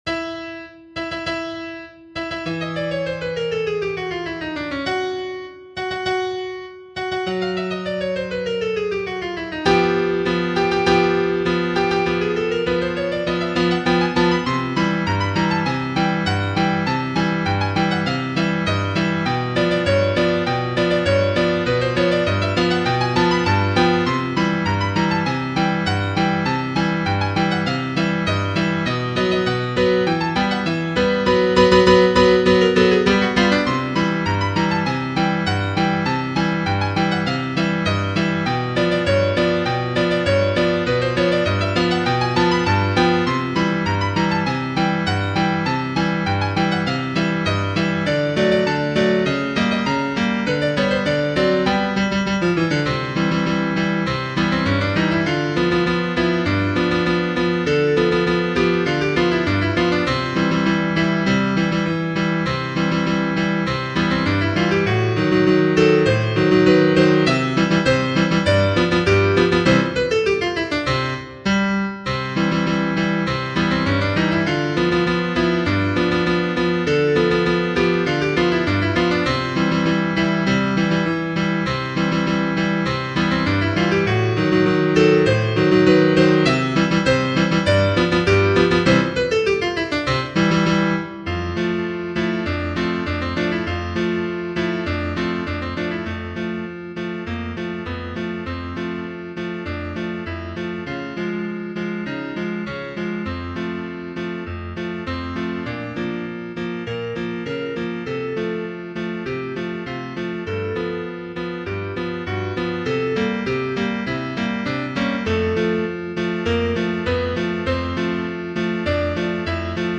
Genere: Romantiche
marcia militare